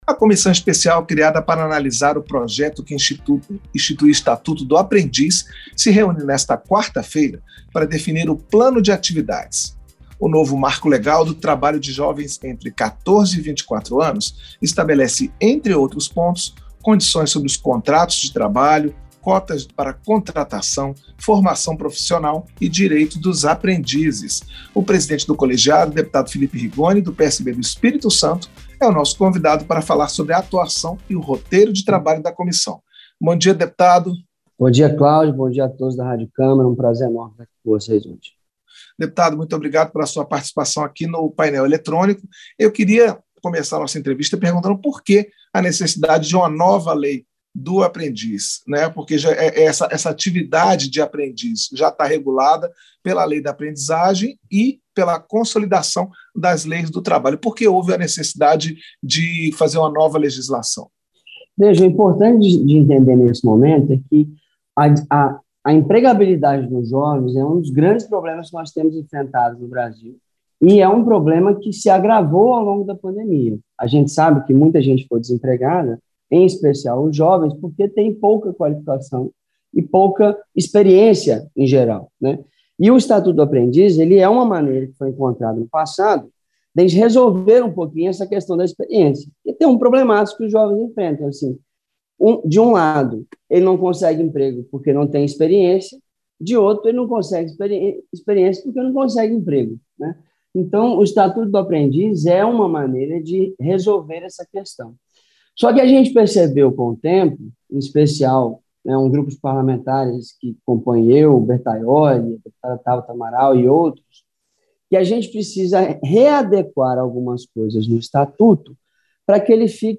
Entrevista - Dep. Felipe Rigoni (PSB-ES)